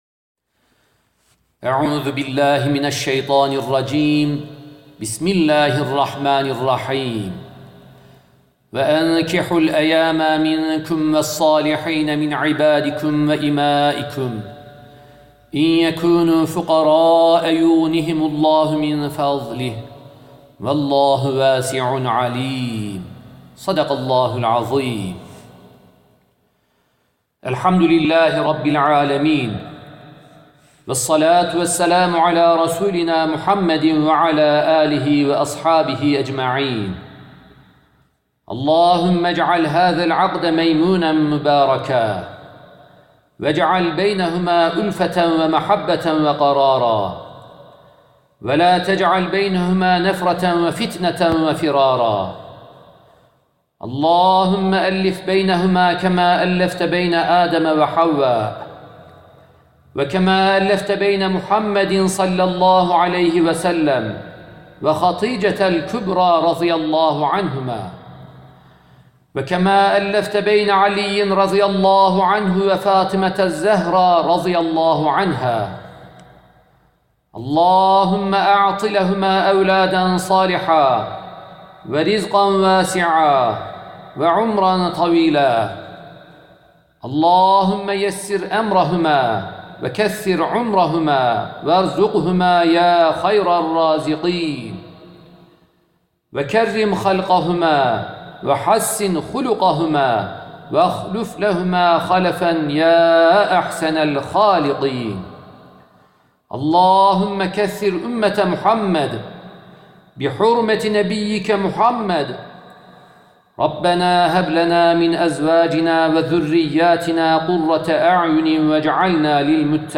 NİKAH DUASI